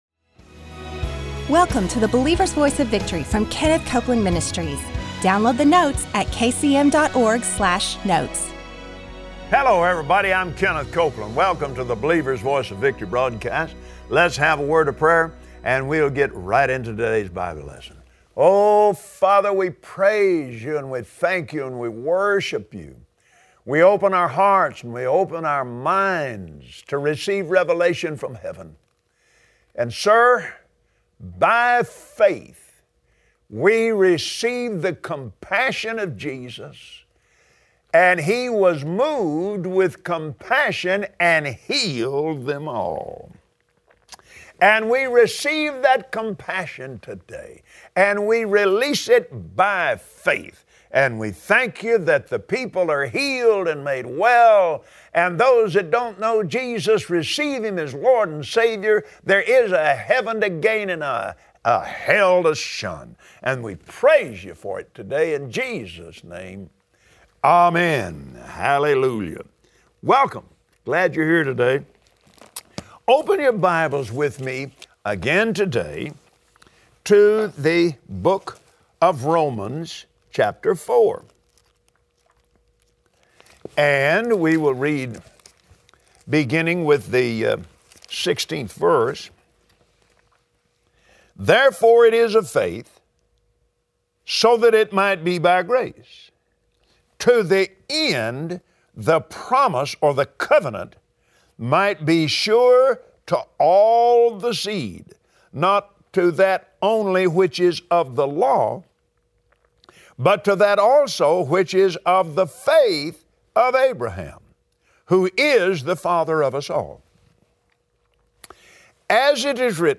Believers Voice of Victory Audio Broadcast for Tuesday 08/09/2016 There is power in the name of Jesus and every believer has the authority to use it. Today on the Believer’s Voice of Victory, Kenneth Copeland takes us through scripture to learn how to release the power that’s in Jesus’ name.